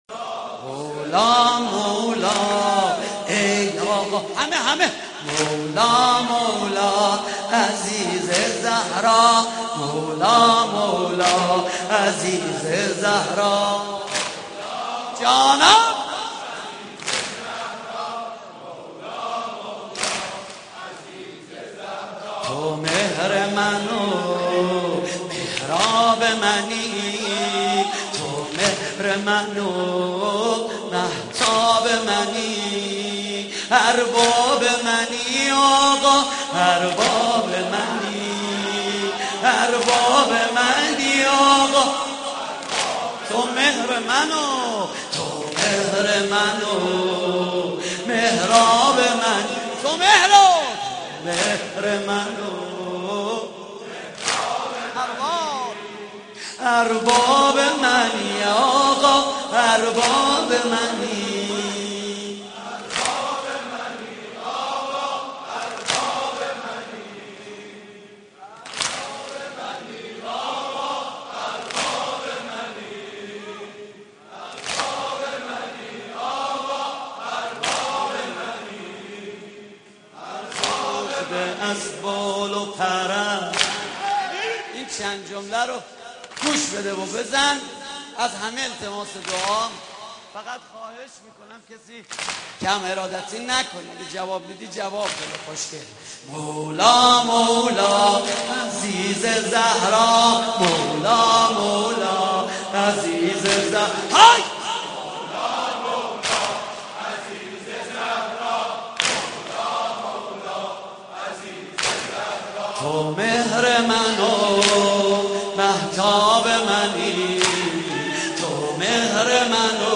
محرم 88 - سینه زنی 5
محرم-88---سینه-زنی-5